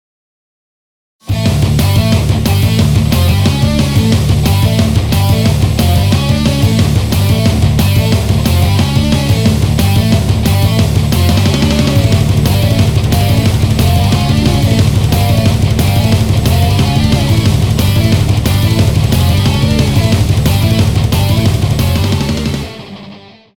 ����-������ "����" (Thrash metal)